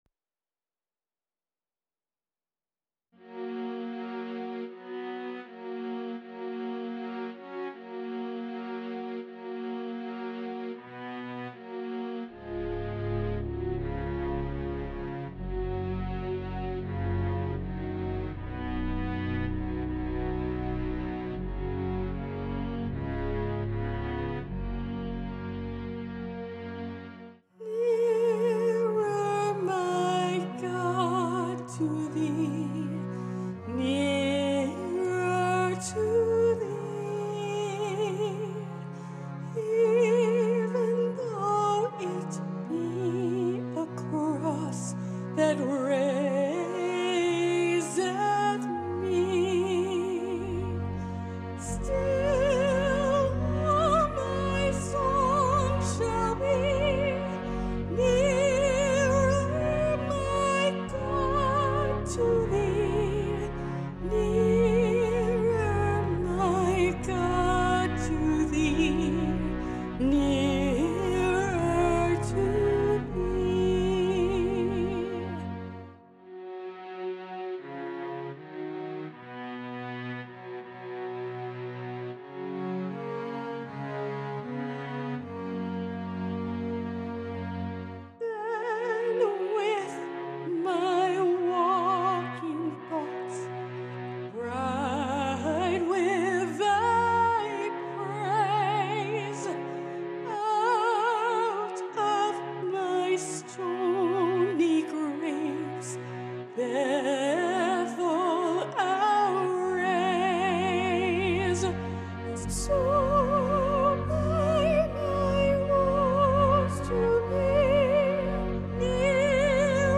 Genre: Hymn, Classical Key: Gb Slow | 79 | 6/8
Celtic Music with a classical female soprano lead